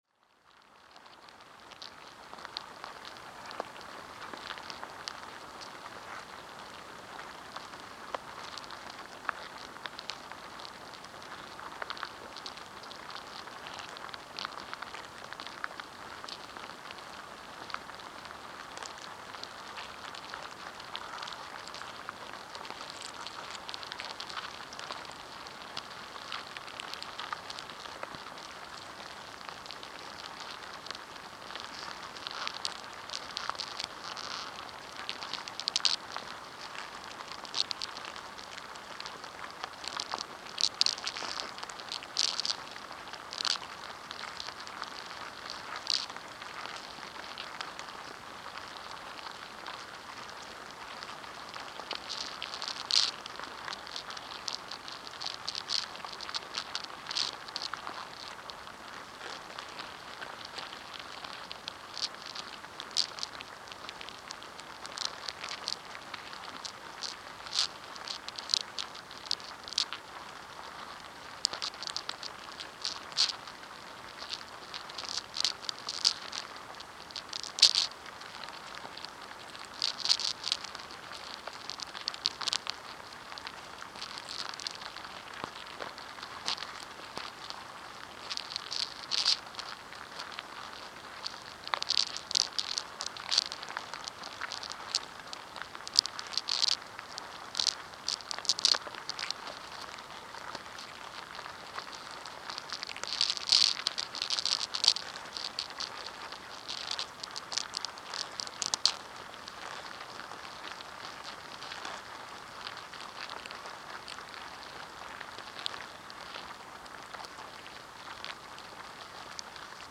Antsnest.mp3